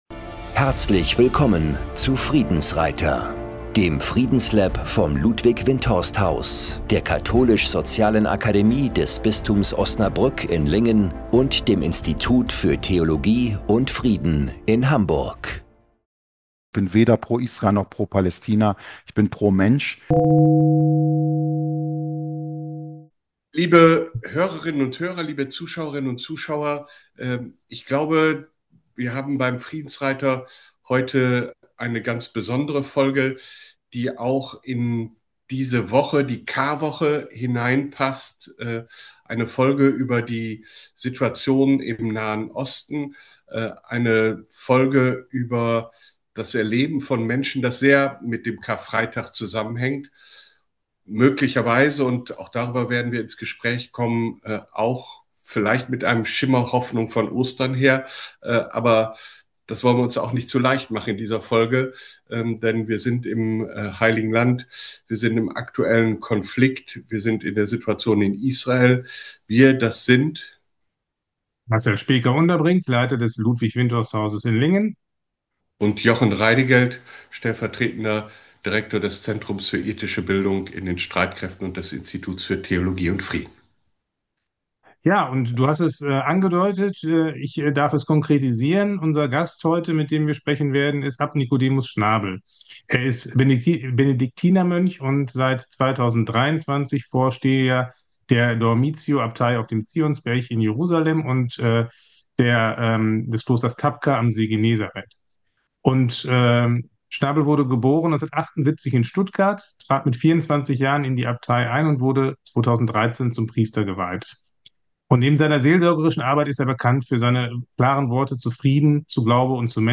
Folge 28 | Das Heilige Land zwischen Karfreitag und Hoffnung | Gast: Abt Nikodemus Schnabel ~ Friedensreiter Podcast